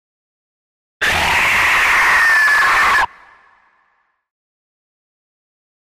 Alien Screech Scream 1 - Monster Dinosaur